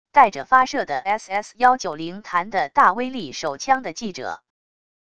带着发射的SS190弹的大威力手枪的记者wav音频